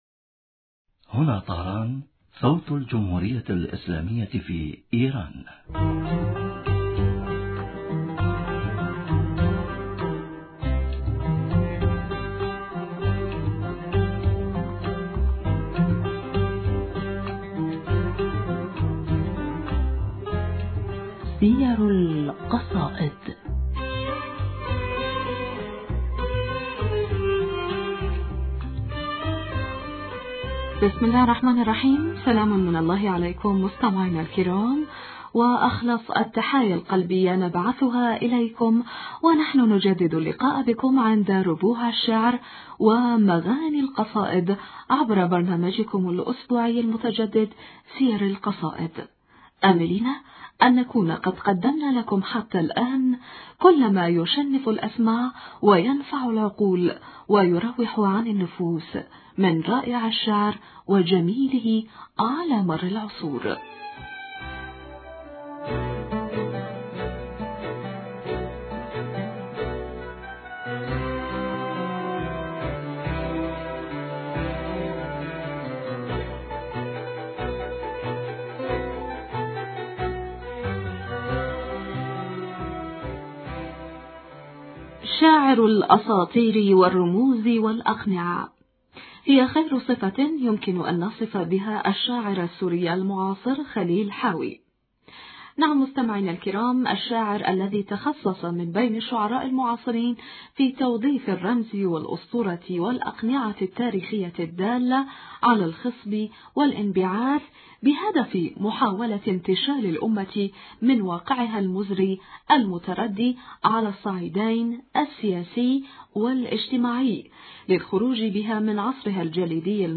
المحاورة: